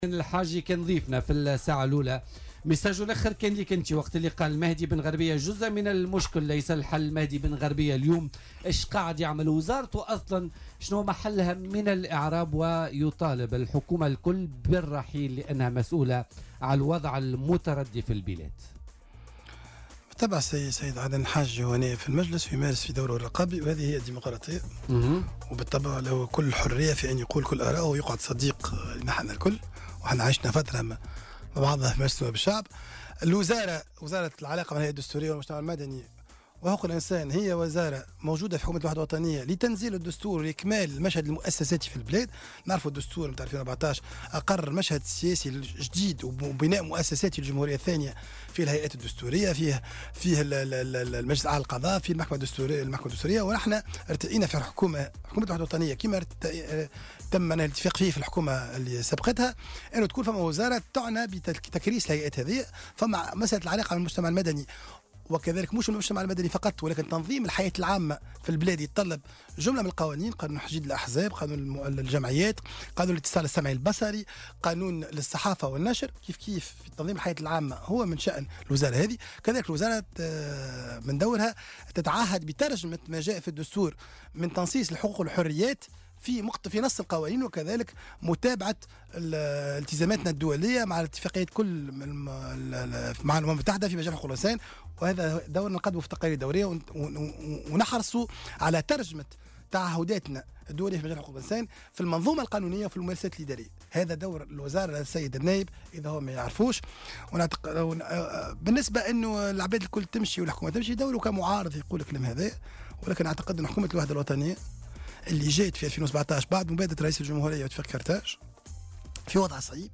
وأضاف بن غربية ضيف برنامج "بوليتيكا" اليوم أن الغاية من وجود وزارة العلاقة مع الهيئات الدستورية والمجتمع المدني وحقوق الإنسان في حكومة الوحدة الوطنية هو استكمال المشهد المؤسساتي في البلاد، والمساعدة على تركيز الهيئات الدستورية، وفق تعبيره.